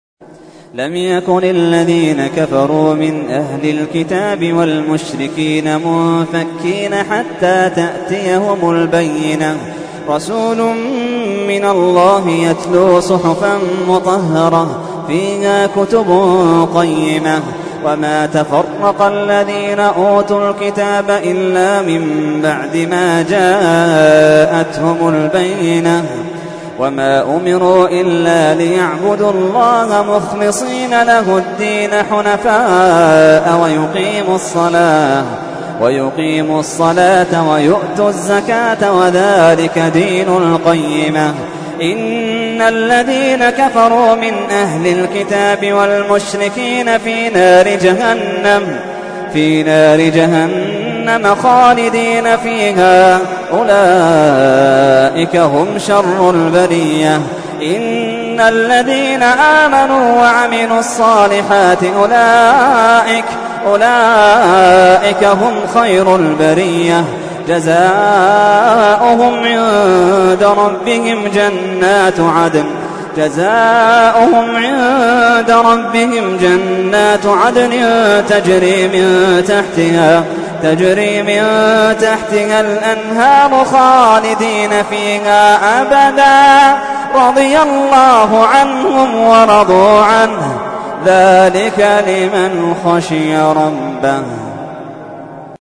تحميل : 98. سورة البينة / القارئ محمد اللحيدان / القرآن الكريم / موقع يا حسين